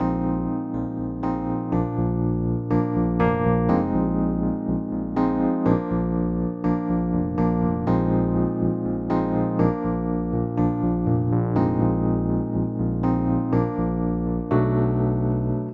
原声钢琴 D小调 122bpm
描述：D小调的原声钢琴循环曲...
标签： 122 bpm RnB Loops Piano Loops 2.65 MB wav Key : D
声道立体声